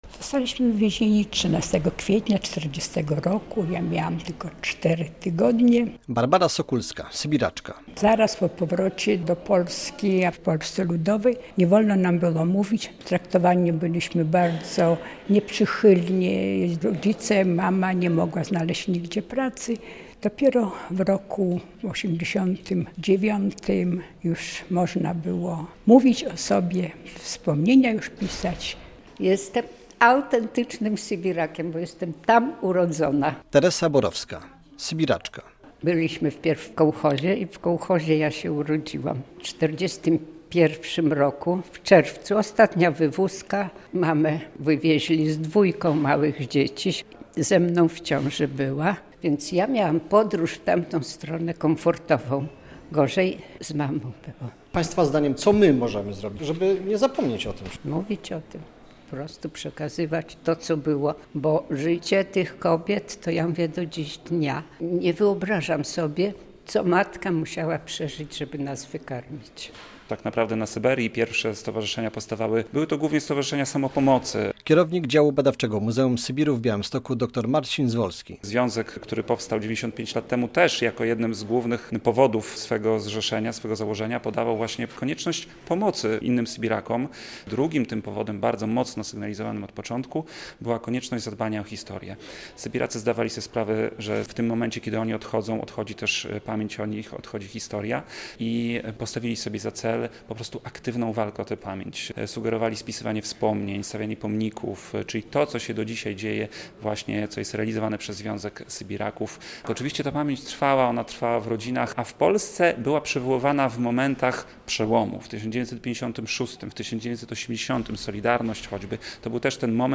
Sytuacja zmieniła się dopiero w 1988 roku, o czym przypominali w środę (21.06) sybiracy, którzy z okazji rocznicy spotkali się w białostockim Muzeum Pamięci Sybiru.